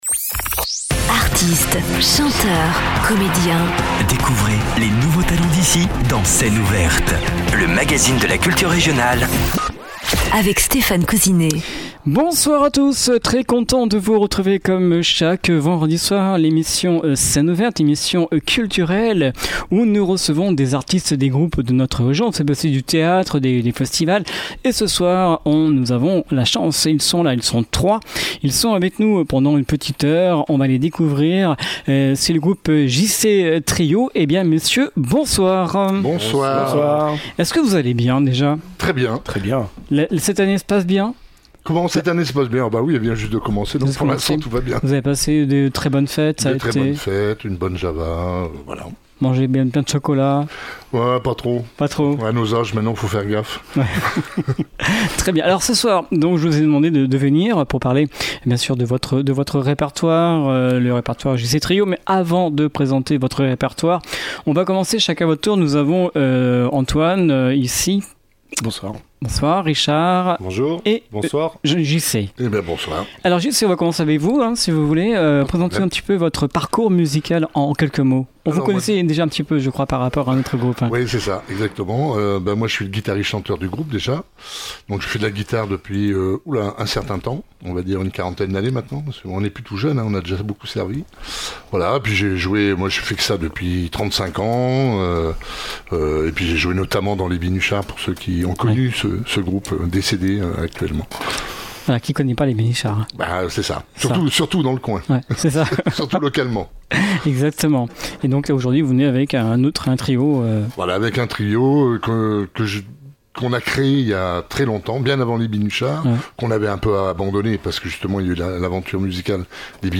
Ce power trio essaie de faire revivre sa musique.